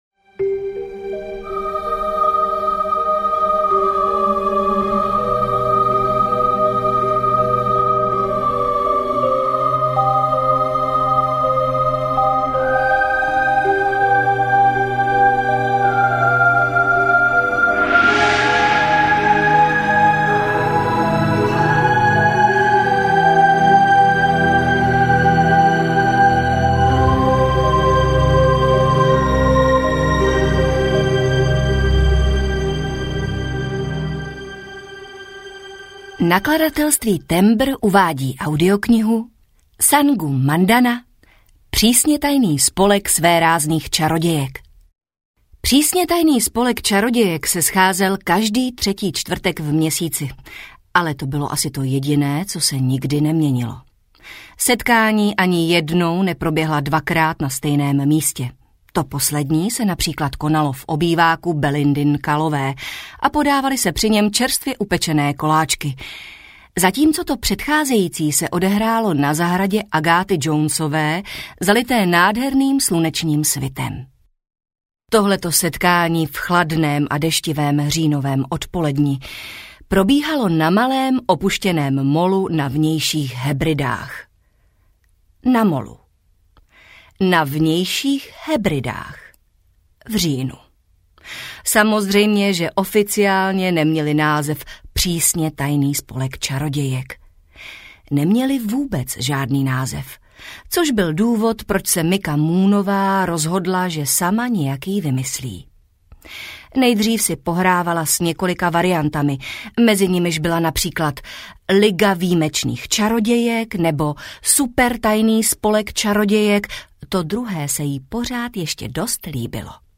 Přísně tajný spolek svérázných čarodějek audiokniha
Ukázka z knihy
prisne-tajny-spolek-sveraznych-carodejek-audiokniha